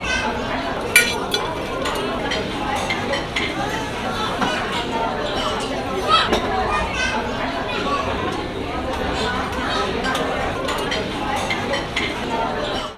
Restaurant In China Sound
ambience